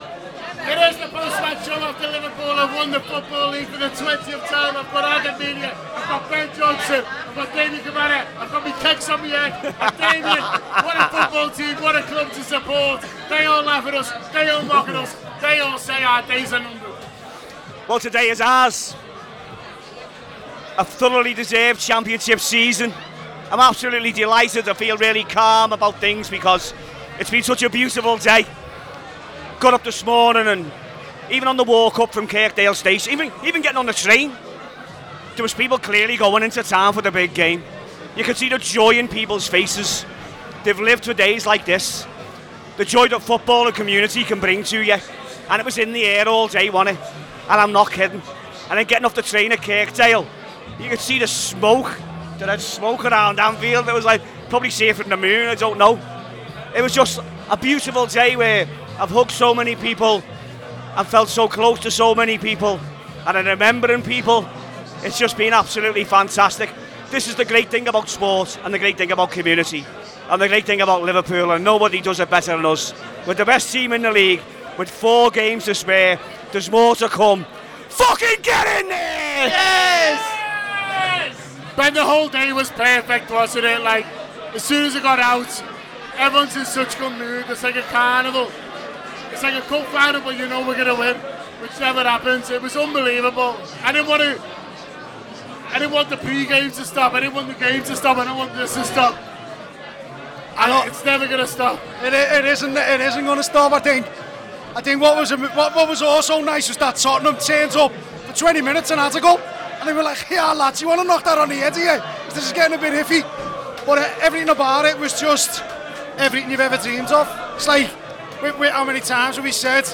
The Anfield Wrap’s post-match reaction podcast after Liverpool secured the League title to become Champions Of England for the 20th time.